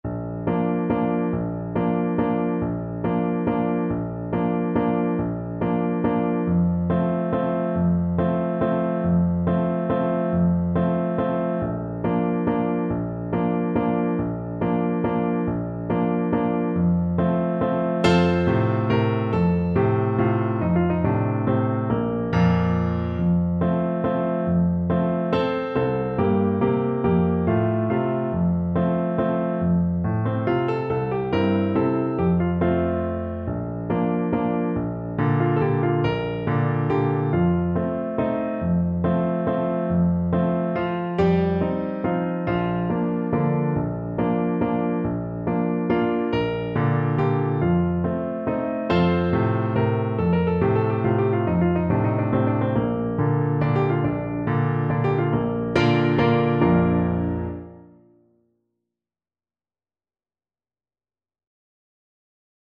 Allegretto = c.140
3/4 (View more 3/4 Music)